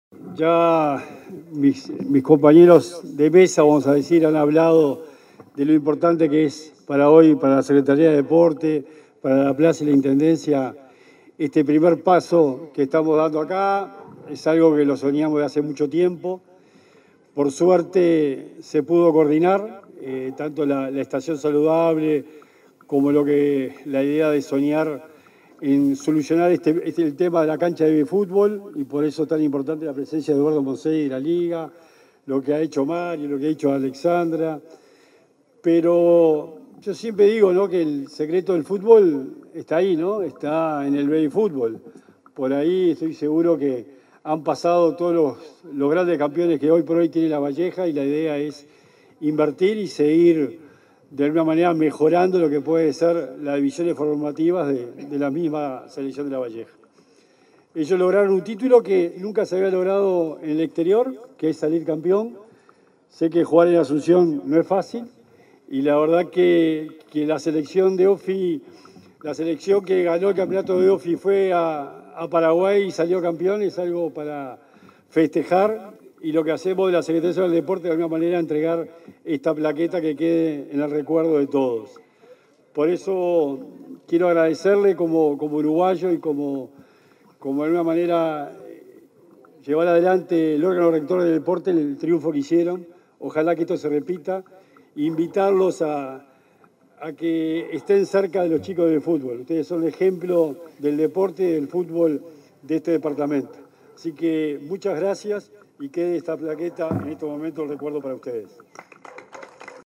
Palabras del secretario nacional del Deporte, Sebastián Bauzá
Palabras del secretario nacional del Deporte, Sebastián Bauzá 03/07/2023 Compartir Facebook X Copiar enlace WhatsApp LinkedIn En el marco de la visita que se junto a la Organización Nacional de Fútbol Infantil (ONFI) a Lavalleja, este 3 de julio, se expresó el secretario nacional del Deporte, Sebastián Bauzá.